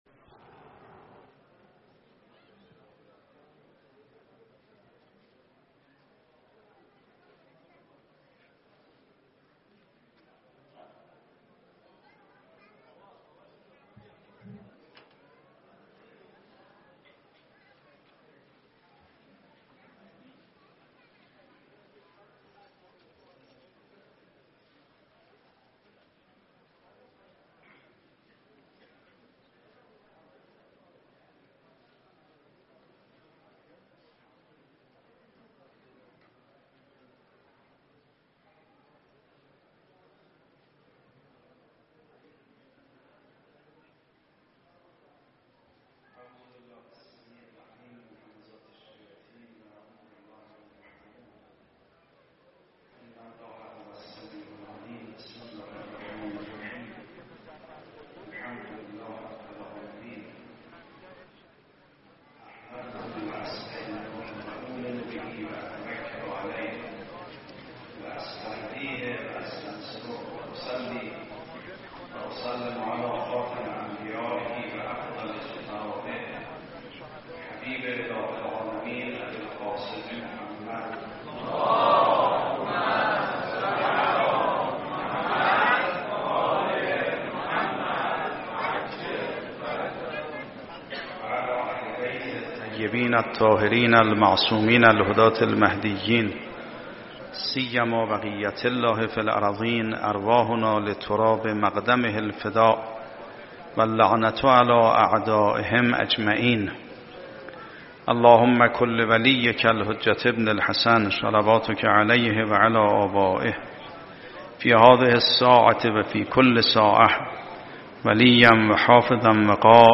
جلسه پنجم هیات ثارالله محرم ۱۴۰۱/